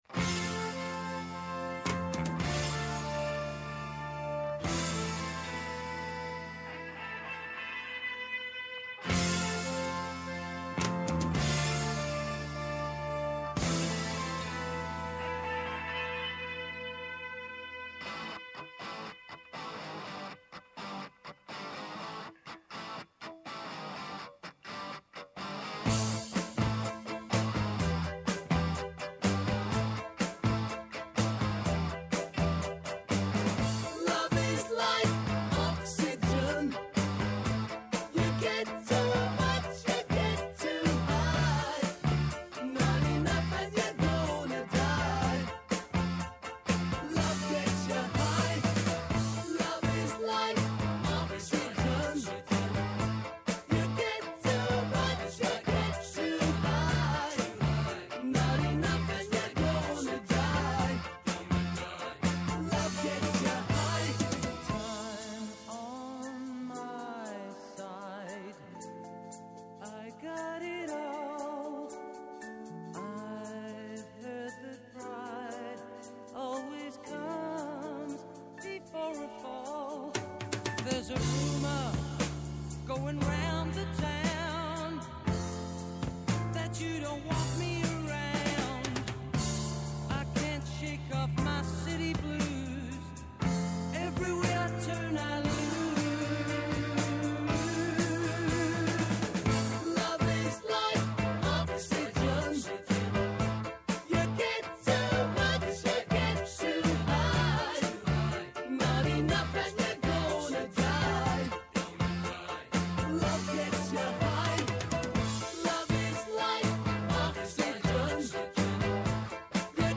Καλεσμένοι σήμερα ο Νίκος Παππάς , βουλευτής ΣΥΡΙΖΑ και πρώην Υπουργός και ο Άδωνις Γεωργιάδης, Υπουργός Υγείας.